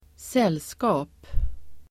Uttal: [²s'el:ska:p]